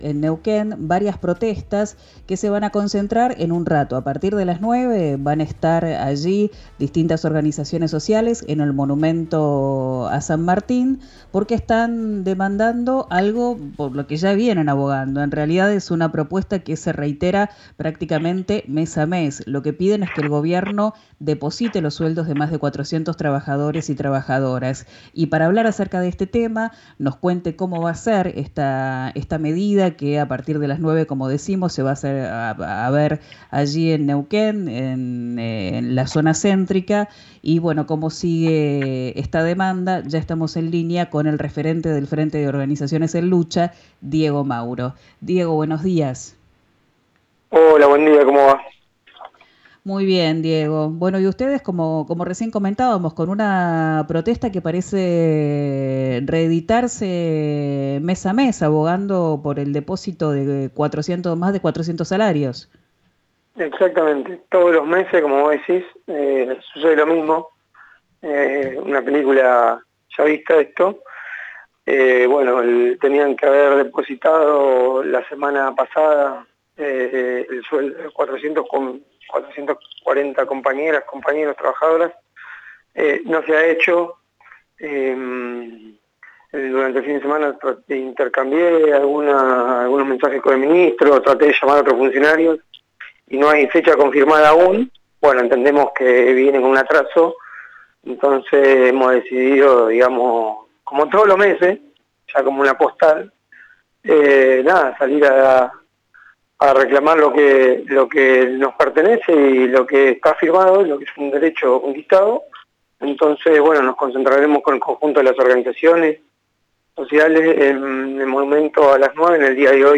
En dialogo con RIO NEGRO RADIO